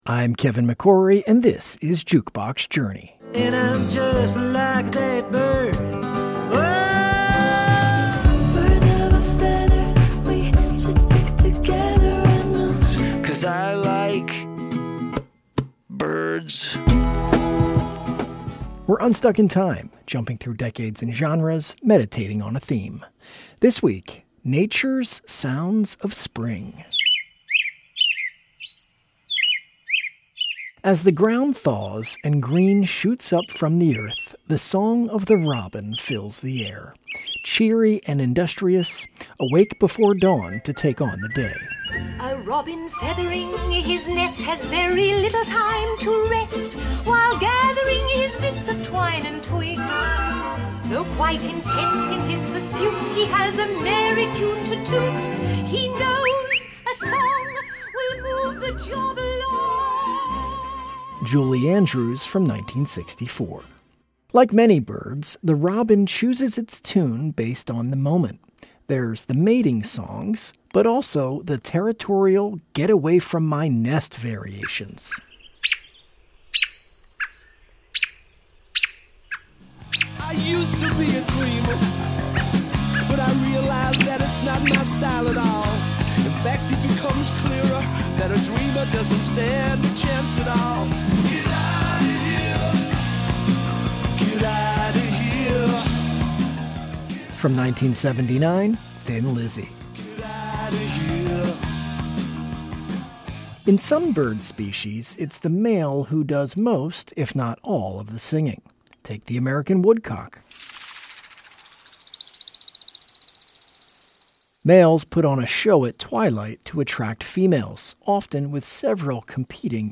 Lace up your boots, on the first day of Spring, Jukebox Journey goes on a hike to hear the sounds of the season.